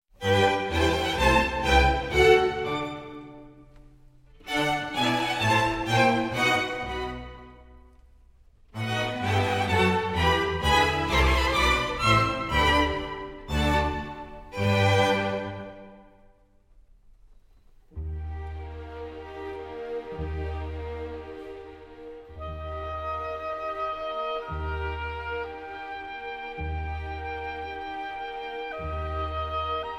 the lyrical oboe in opera and cinema
oboe, oboe d'amore
Concerto in E-Flat Major